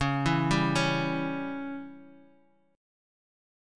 C#dim Chord